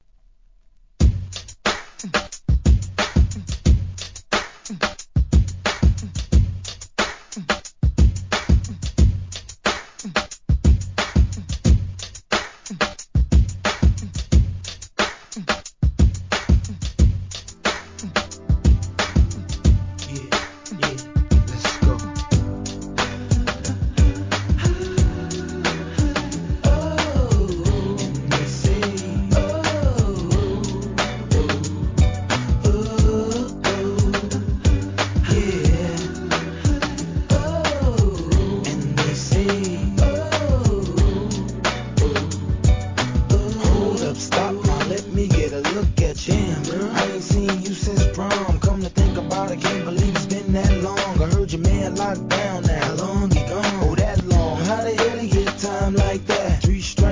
HIP HOP/R&B
DJには使い易いようにイントロ、アウトロがエディットされた人気シリーズ
(97bpm)